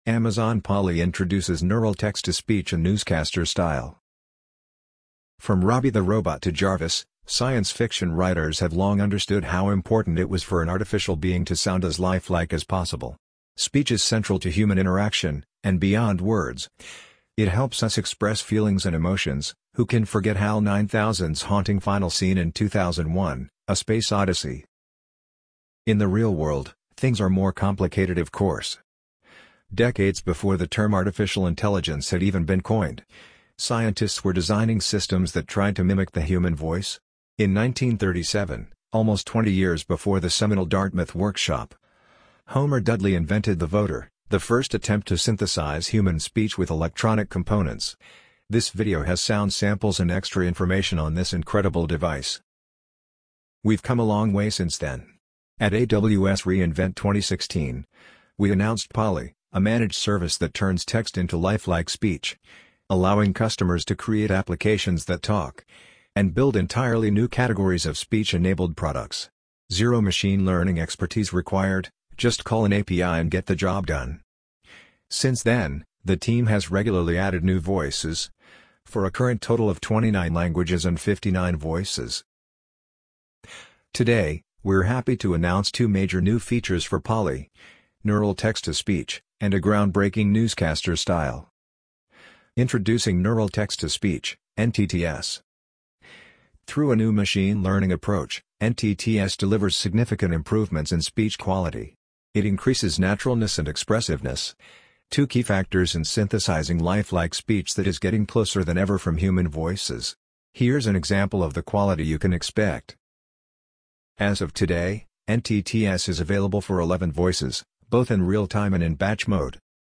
AWS’ new text-to-speech engine sounds like a newscaster
The new newscaster style is now available in two U.S. voices (Joanna and Matthew) and Amazon is already working with USA Today and Canada’s The Globe and Mail, among a number of other companies, to help them voice their texts.